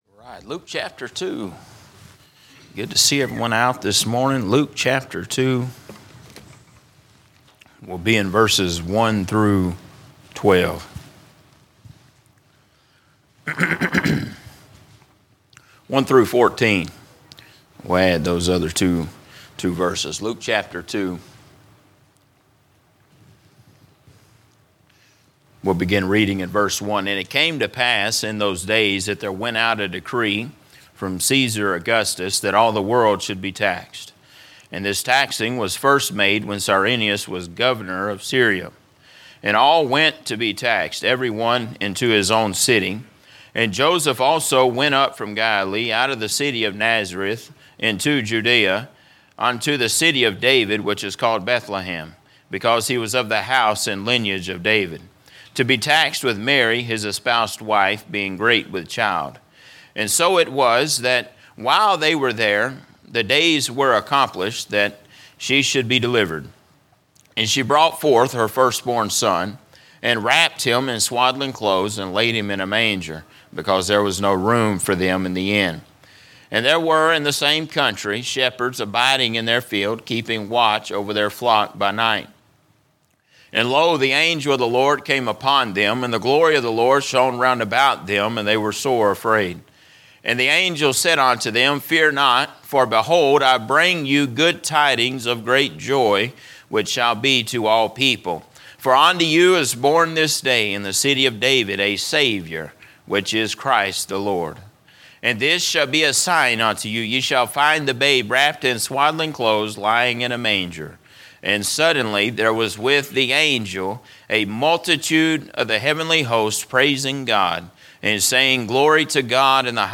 A message from the series "General Preaching." A look at the birth of Christ